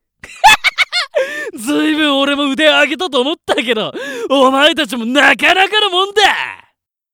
ボイス
性別：男
何かと荒い口調や態度で物事を発する一面を持っている。